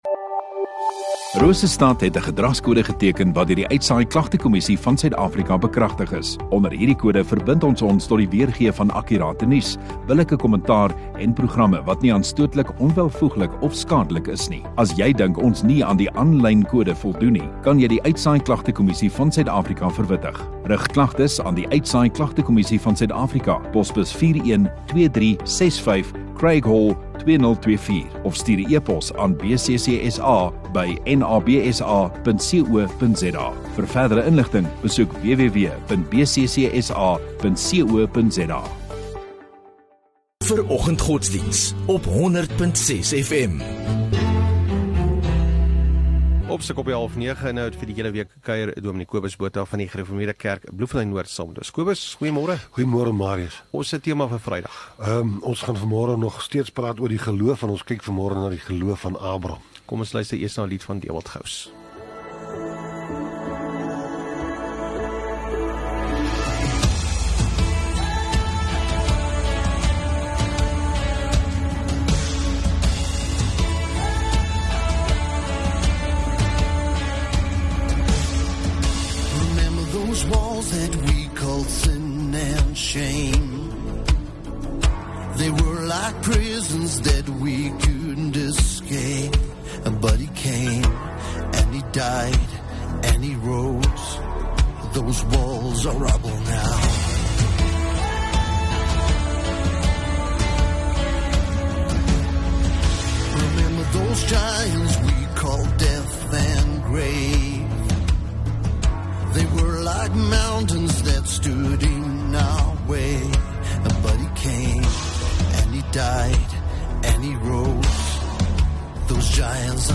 16 Aug Vrydag Oggenddiens